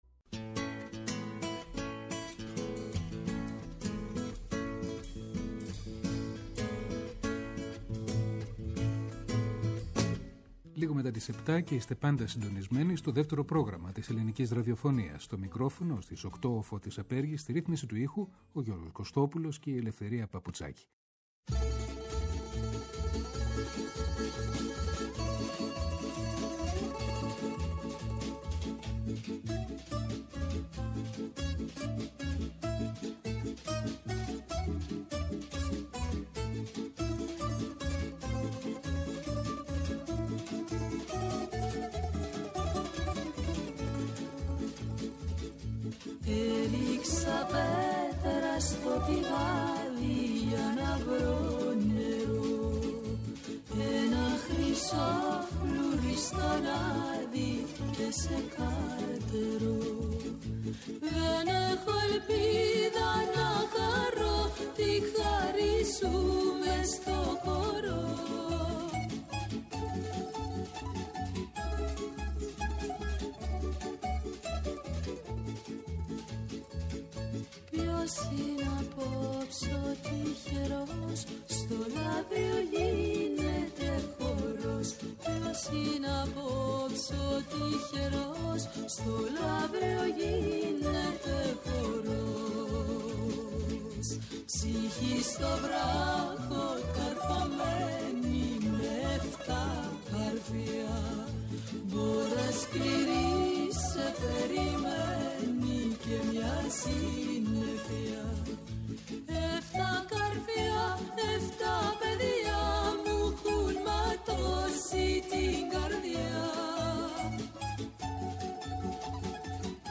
ΔΕΥΤΕΡΟ ΠΡΟΓΡΑΜΜΑ Αφιερώματα Μουσική Συνεντεύξεις